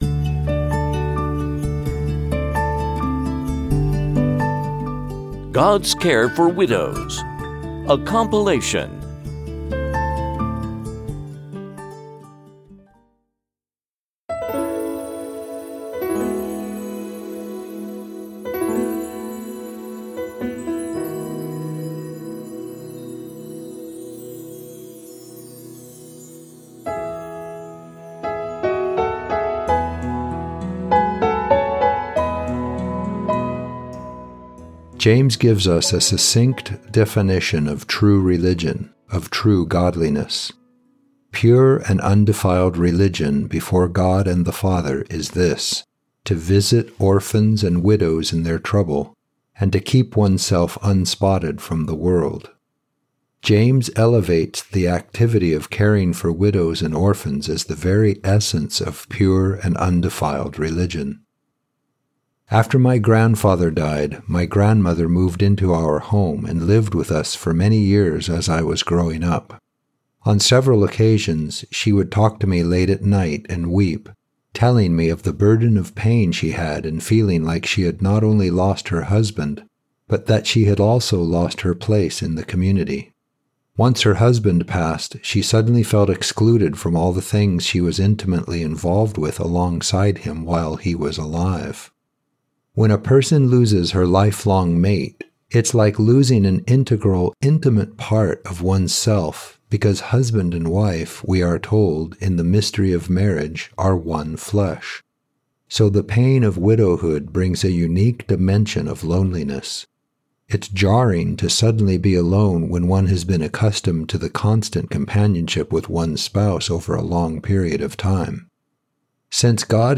TFI_Devotional_Gods_Care_for_Widows.mp3